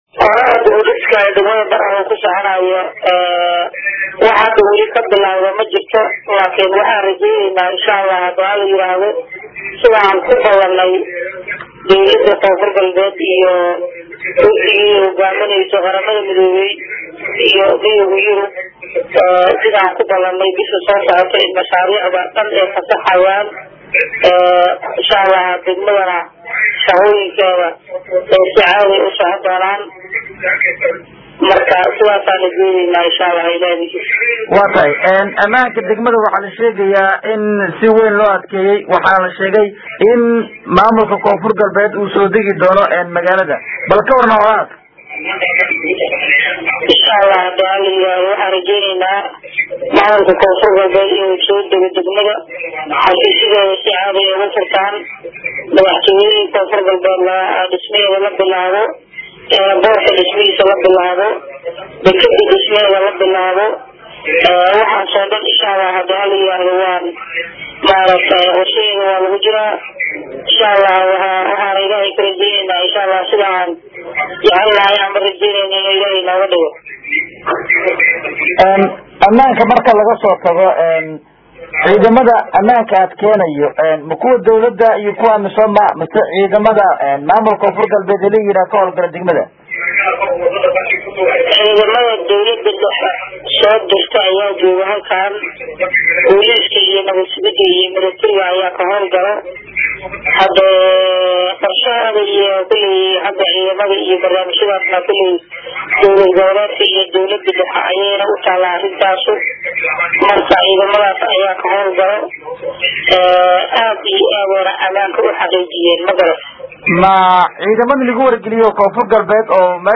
Dhageys Wareysi: Maamulka Degmada Baraawe Oo Ka Hadley Mashaariic Horumarineed Oo Dowladda KGS Halkaasi Ka Fulineyso